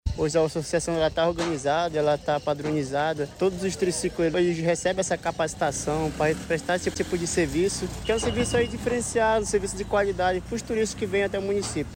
SONORA-4-TEMPORADA-CRUZEIROS-PARINTINS-.mp3